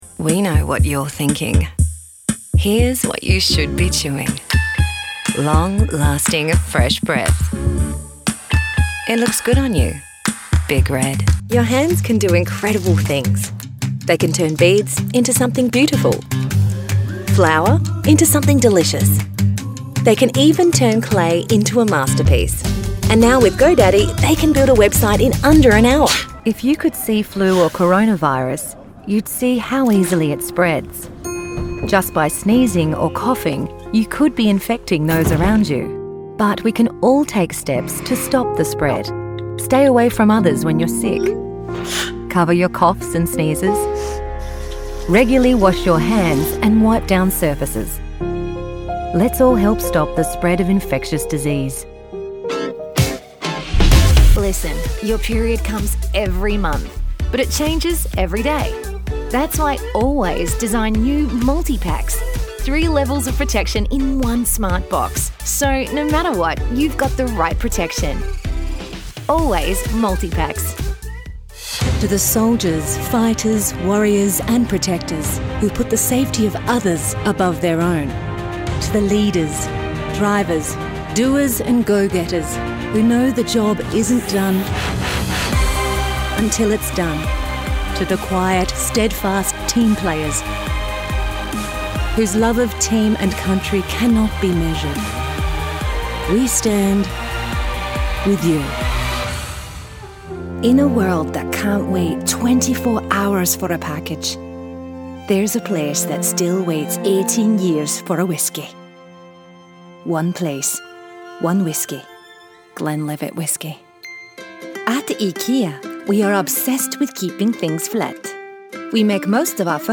Voix off en Anglais (Australie)
Commerciale, Enjouée, Polyvalente
Commercial